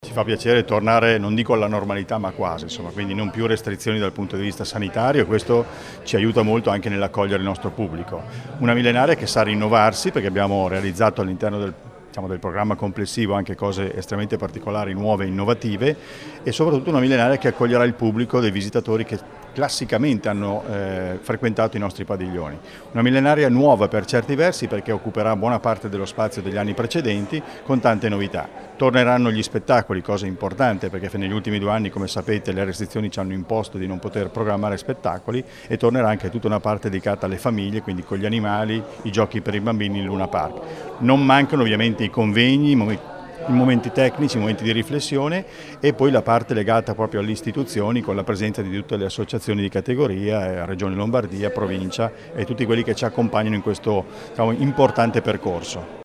Le interviste
Ecco ai nostri microfoni le dichiarazioni a seguito della conferenza stampa dedicata all’evento: